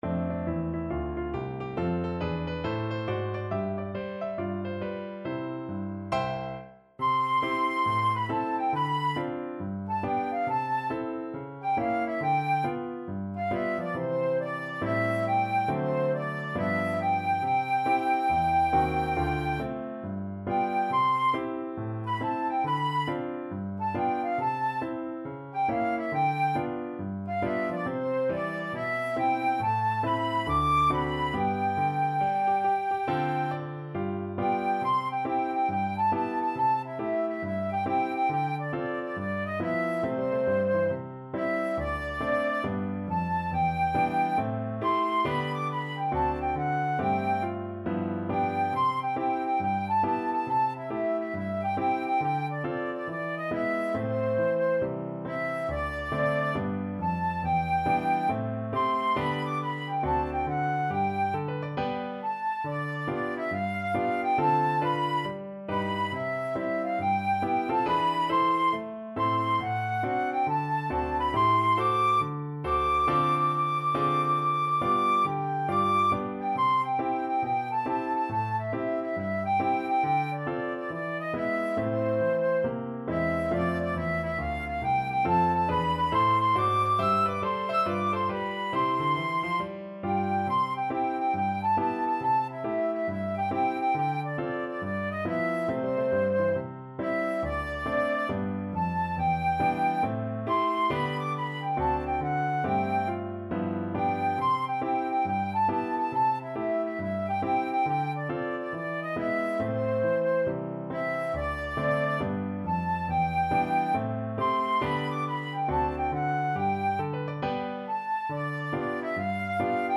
With a swing =c.69
4/4 (View more 4/4 Music)
Pop (View more Pop Flute Music)